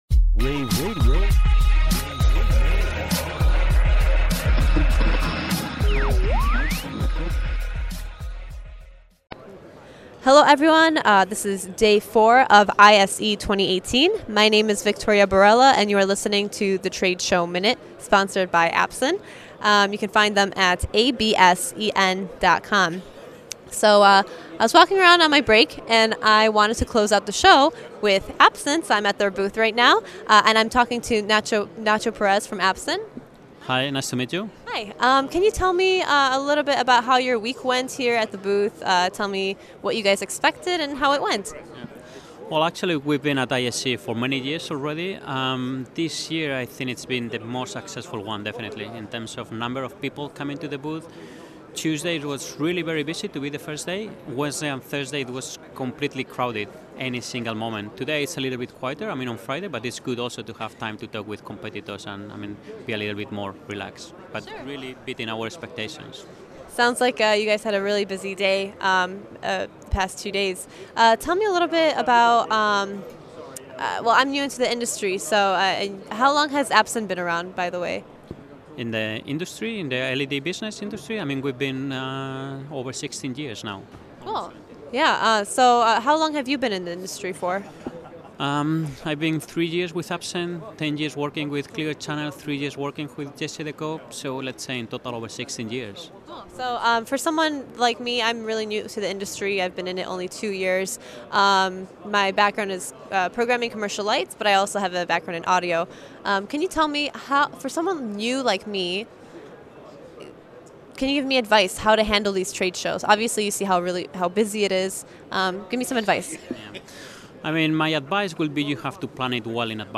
February 9, 2018 - ISE, ISE Radio, Radio, The Trade Show Minute,